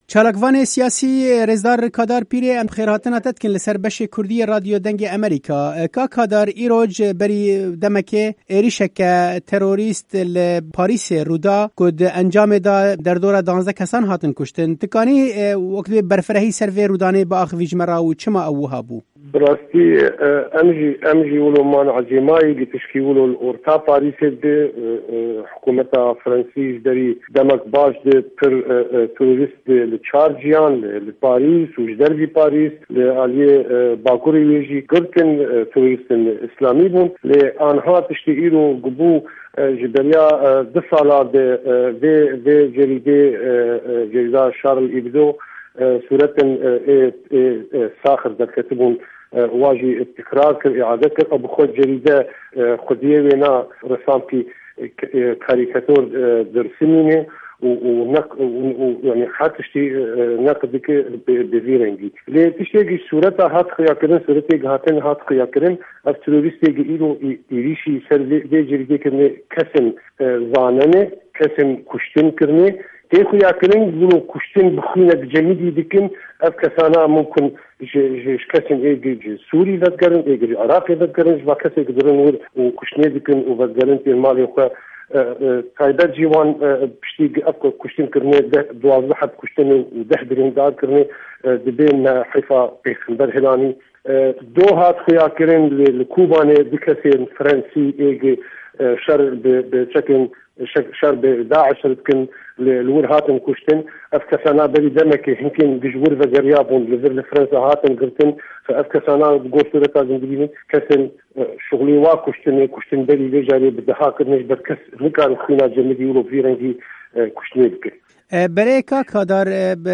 دوو وتووێژ له‌ باره‌ی هێرشه‌که‌ی سه‌ر گۆڤاری شـارلی ئێبدۆی فه‌ڕه‌نسایی